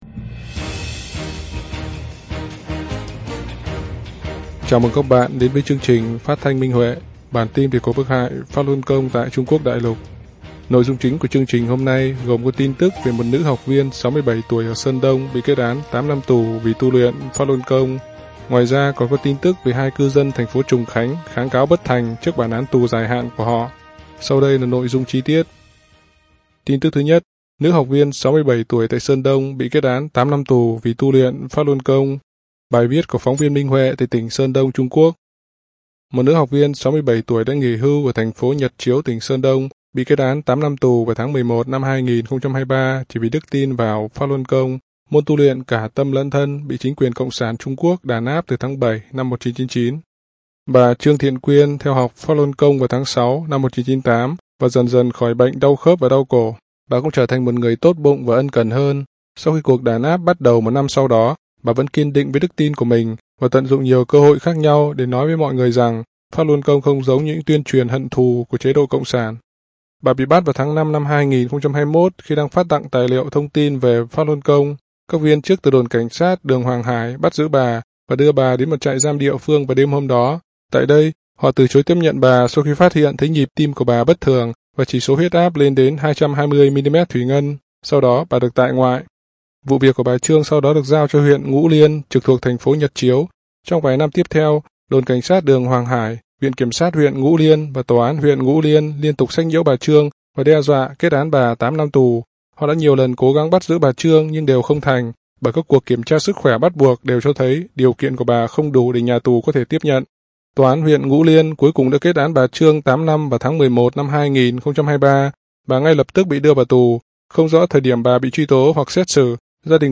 Chương trình phát thanh số 75: Tin tức Pháp Luân Đại Pháp tại Đại Lục – Ngày 09/02/2024